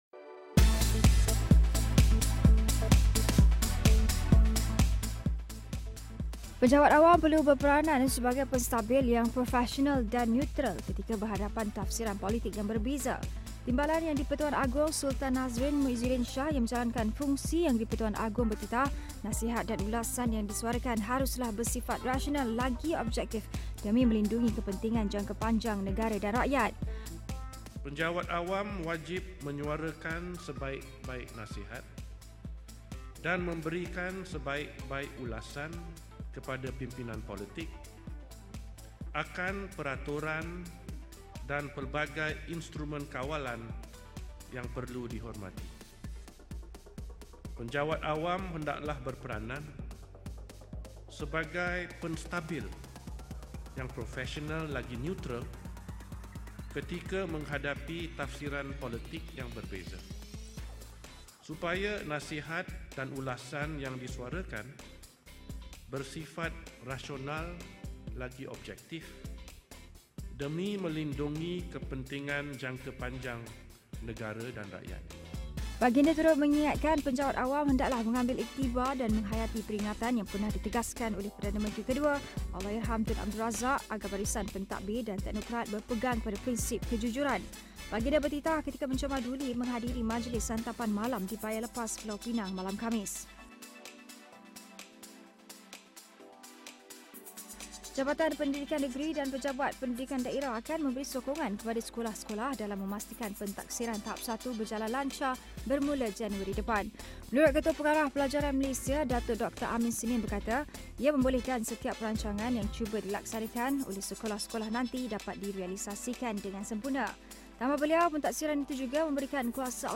Ikuti rangkuman berita utama yang menjadi tumpuan sepanjang hari di Astro AWANI menerusi AWANI Ringkas.